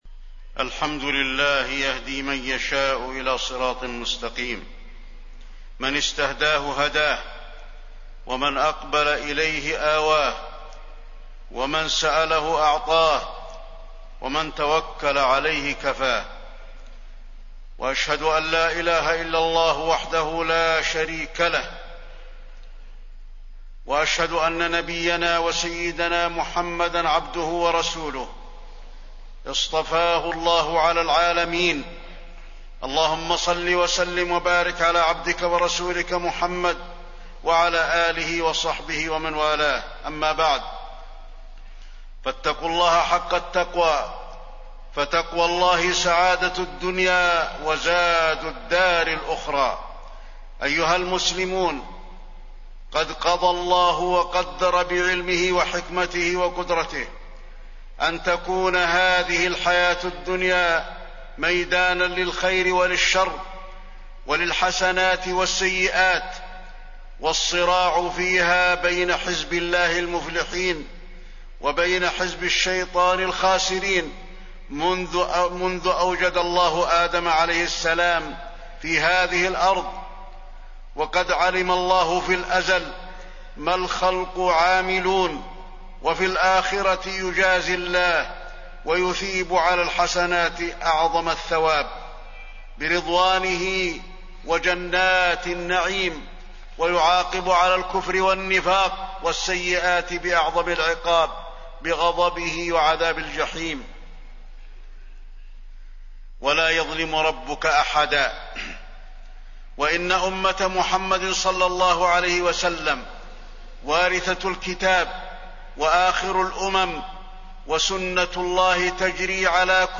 تاريخ النشر ١٠ صفر ١٤٣٢ هـ المكان: المسجد النبوي الشيخ: فضيلة الشيخ د. علي بن عبدالرحمن الحذيفي فضيلة الشيخ د. علي بن عبدالرحمن الحذيفي الفتن وموقف المسلم منها